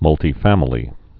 (mŭltē-fămə-lē, -tī-)